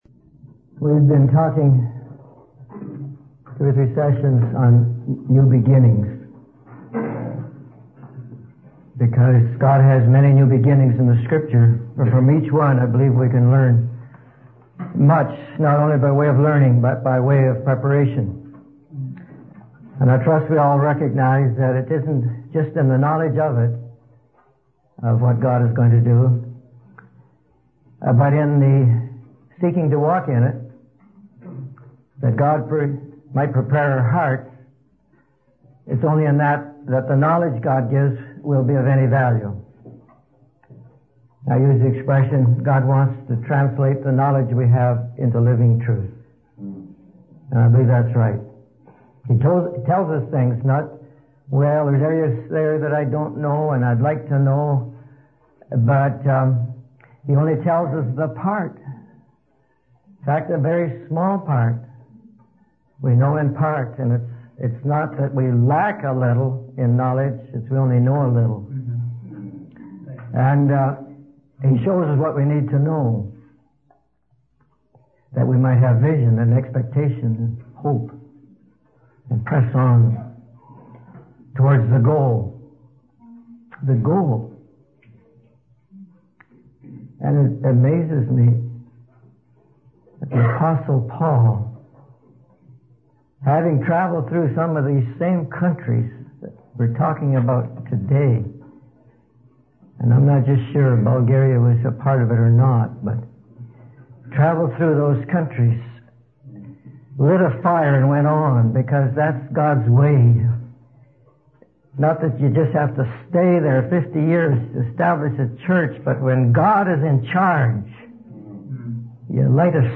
In this sermon, the speaker emphasizes the importance of not looking back and instead focusing on moving forward in faith. He highlights the faithfulness of Moses and how his obedience allowed Joshua to learn the ways of the Lord.